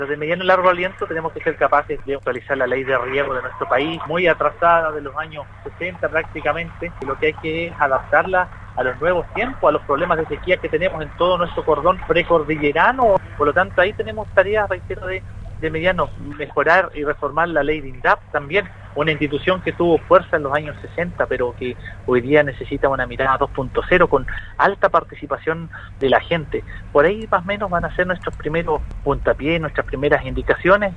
Este último, en medio de la votación para la confirmación de la primera mesa directiva del período, que durará siete meses, contestó el llamado de Radio Sago para comentar sus sensaciones y proyecciones para el nuevo trabajo parlamentario.